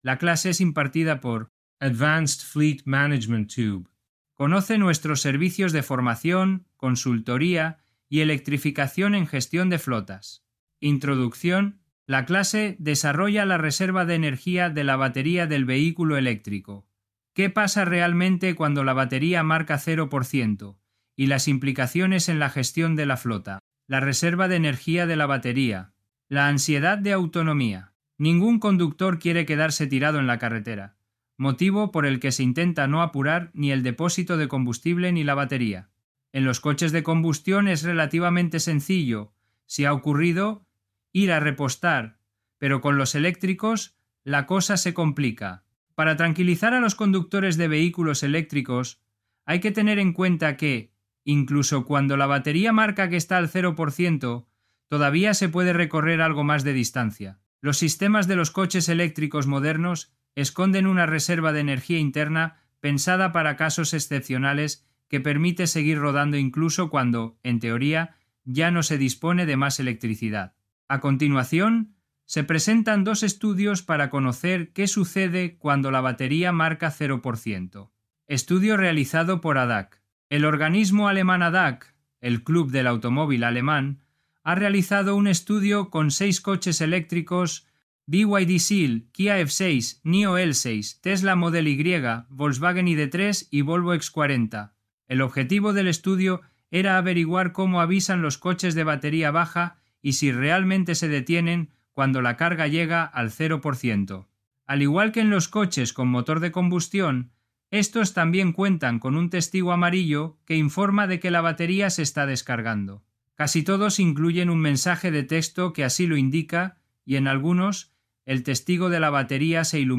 La clase desarrolla la reserva de energía de la batería del vehículo eléctrico, que pasa realmente cuando la batería marca 0%, y las implicaciones en la gestión de la flota.